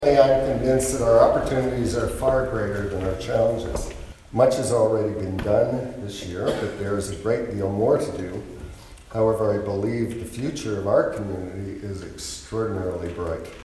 It was a full house on Sunday afternoon at Macaulay Church for the annual levee and Mayor Steve Ferguson is determined to see 2020 be a year the County emerges even stronger.
FERGUSON-LEVEE.mp3